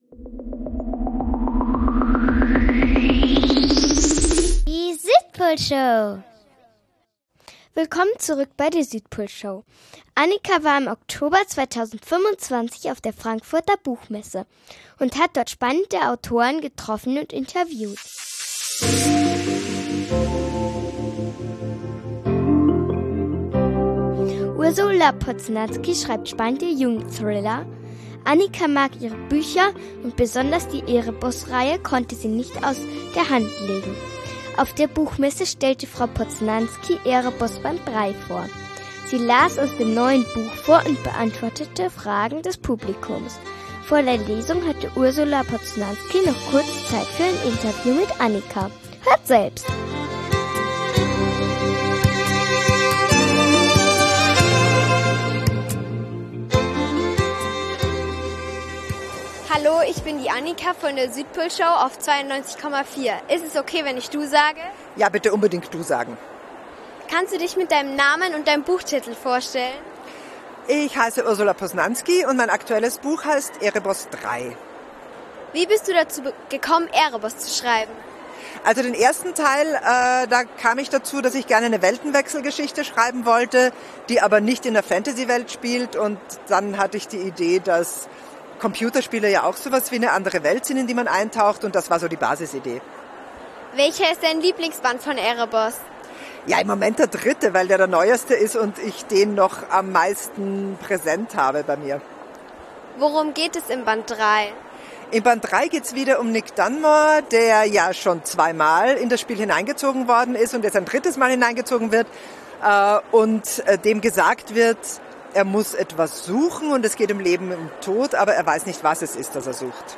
Interview mit Ursula Poznanski zu "Erebos 3" | Buchtipp ~ Südpolshow | Radio Feierwerk 92,4 Podcast
Ursula Poznanski hat auf der Frankfurter Buchmesse 2025 "Erebos 3 " vorgestellt und daraus vorgelesen.